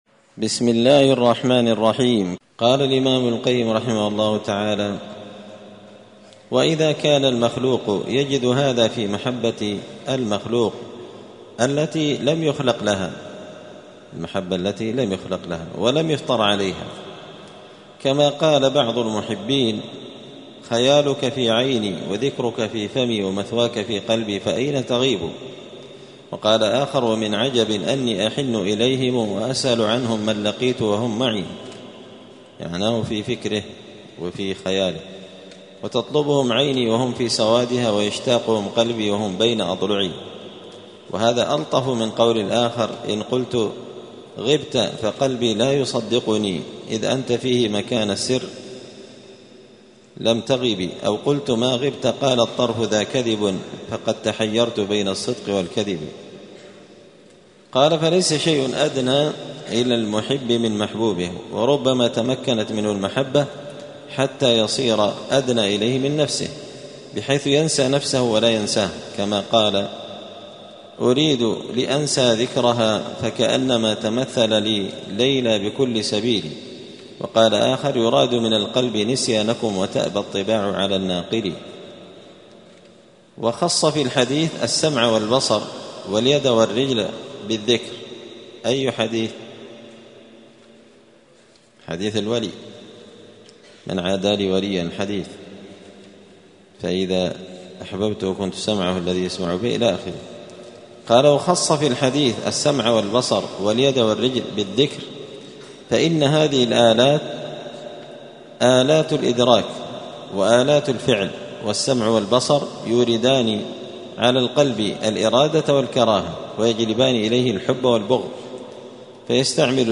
*الدرس التاسع والسبعون (79) فصل آخر مراتب الحب*